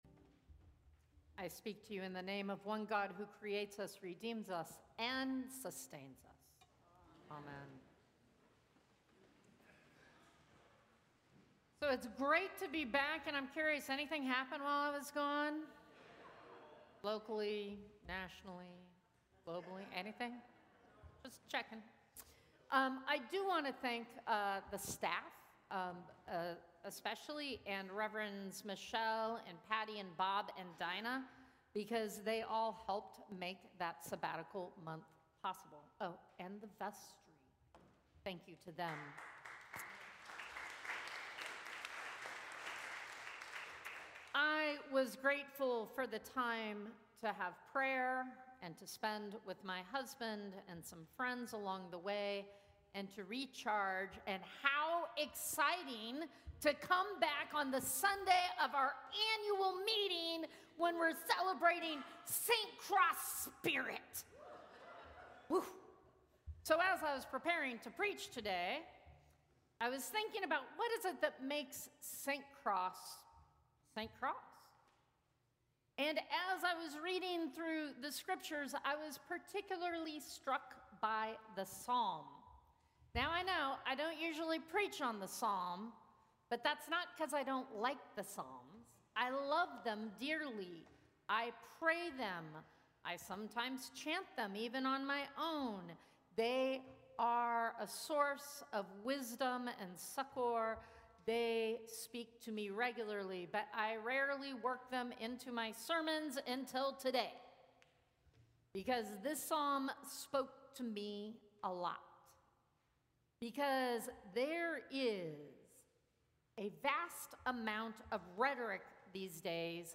Sermons from St. Cross Episcopal Church Fourth Sunday after the Epiphany